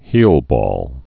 (hēlbôl)